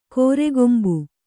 ♪ kōregombu